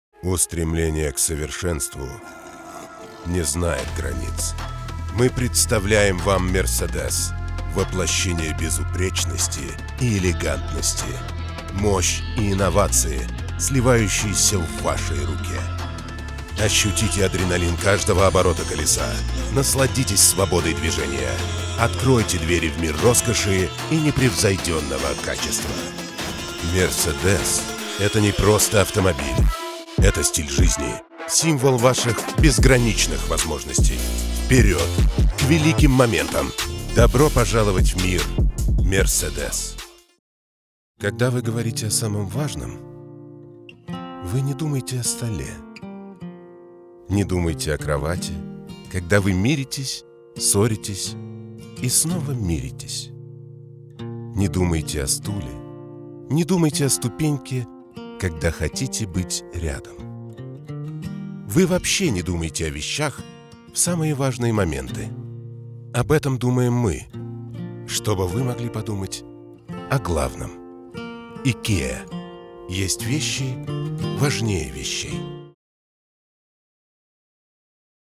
Сейчас диктор:
Актер дубляжа, диктор, а так же актер театра и кино.
Профессиональное оборудование, дикторская кабинка.
Спасибо! Голос великолепный